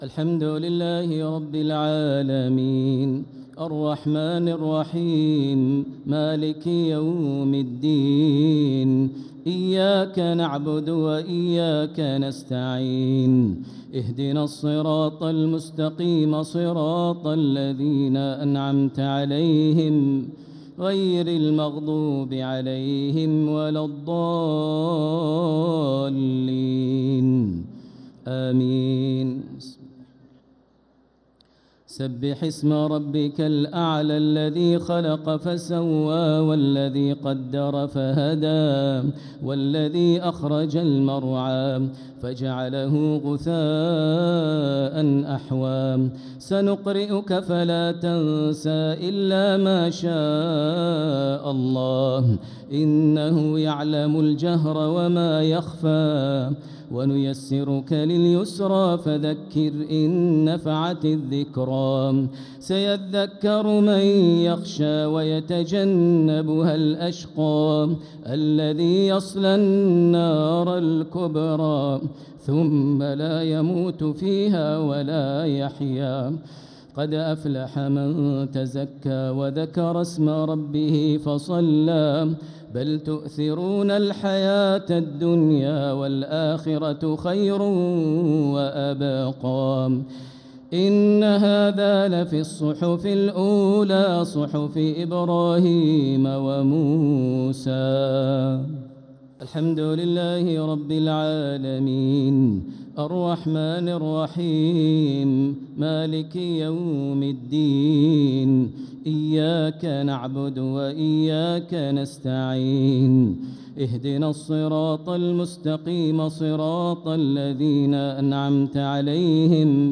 صلاة الشفع و الوتر ليلة 2 رمضان 1446هـ | Witr 2nd night Ramadan 1446H > تراويح الحرم المكي عام 1446 🕋 > التراويح - تلاوات الحرمين